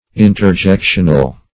Interjectional \In`ter*jec"tion*al\, a.